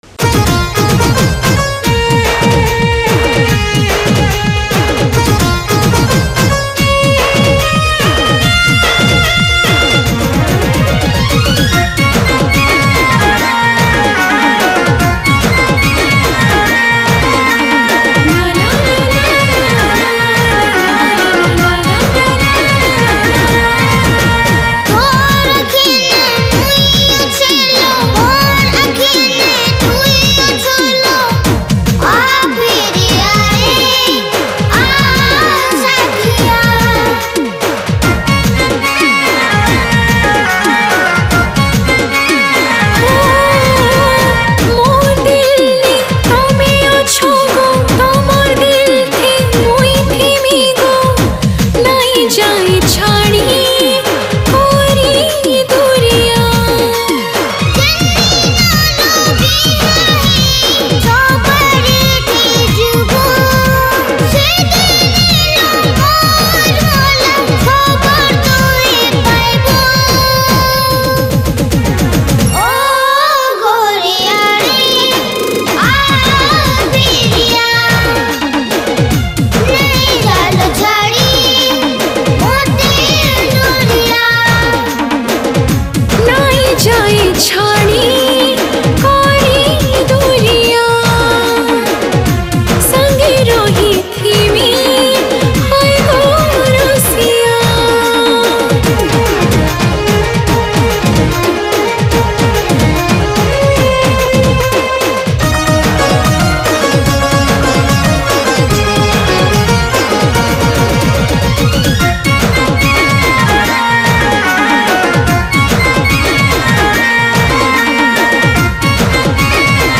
Sambapuri Single Song 2022 Songs Download